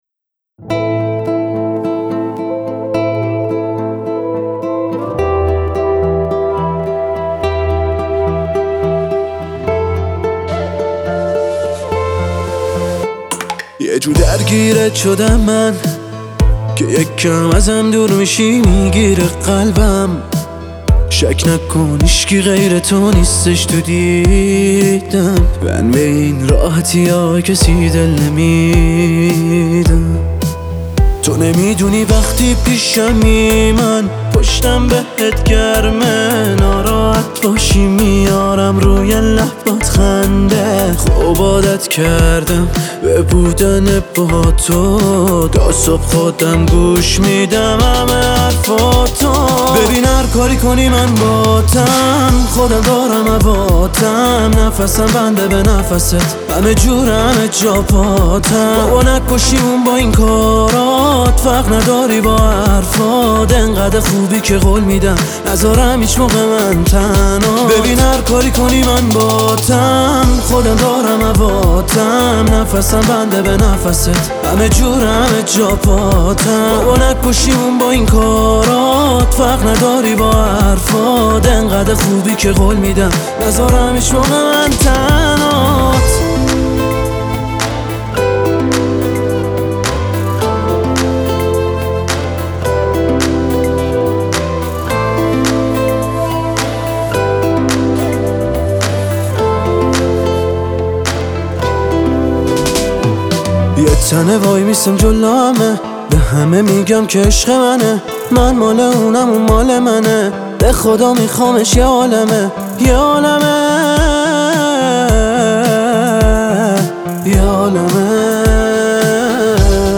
با ملودی دلنشین و صدای پر احساس